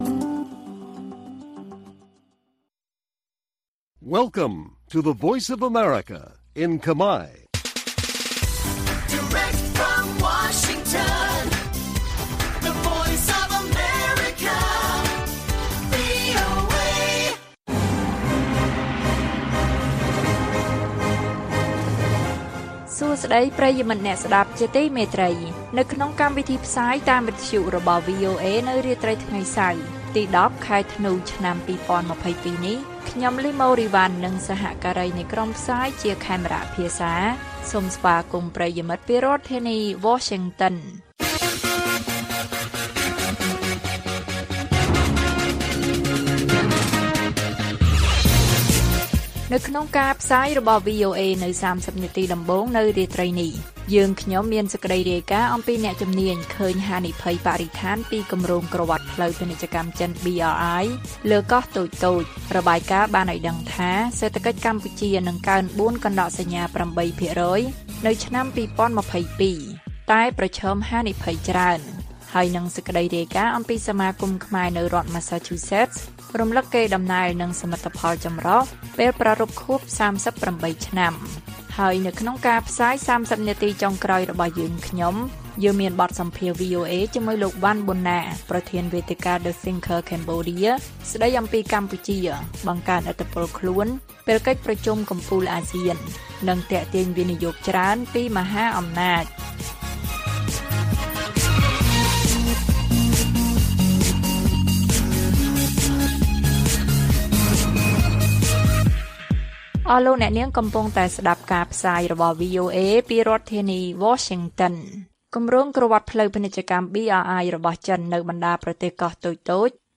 ព័ត៌មាននៅថ្ងៃនេះមានដូចជា អ្នកជំនាញឃើញហានិភ័យបរិស្ថានពីគម្រោងក្រវាត់ផ្លូវពាណិជ្ជកម្មចិន BRI លើកោះតូចៗ។ បទសម្ភាសន៍ VOA៖ កម្ពុជាបង្កើនឥទ្ធិពលខ្លួនពេលកិច្ចប្រជុំកំពូលអាស៊ាន និងទាក់ទាញវិនិយោគច្រើនពីមហាអំណាច និងព័ត៌មានផ្សេងទៀត៕